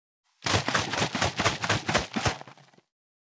344ペットボトル,ワインボトルを振る,水の音,ジュースを振る,シャカシャカ,カシャカシャ,チャプチャプ,ポチャポチャ,シャバシャバ,チャプン,ドボドボ,グビグビ,
ペットボトル